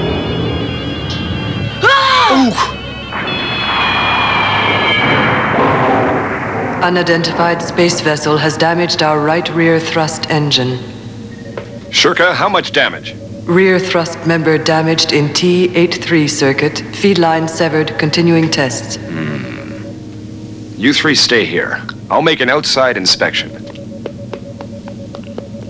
Due to a collision with a “Ahhhh” and “Ohhhh” (or maybe it was an unidentified space vessel), the ship suffer a little damage.